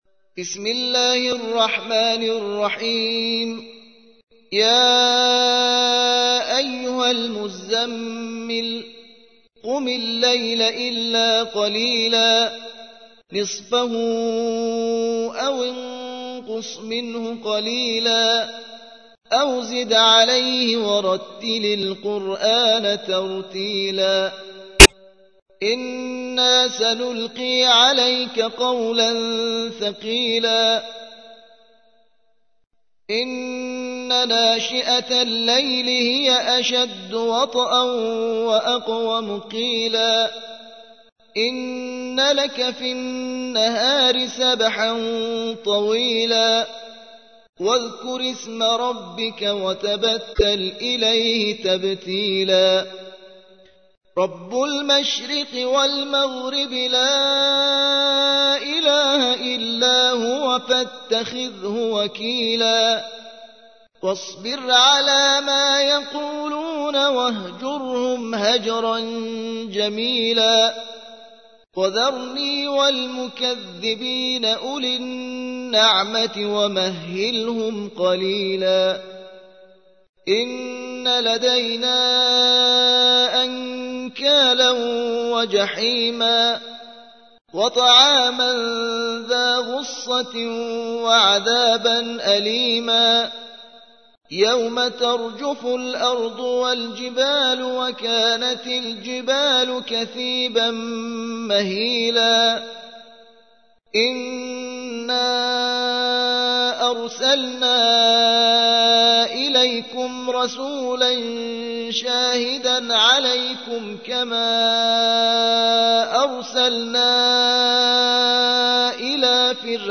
73. سورة المزمل / القارئ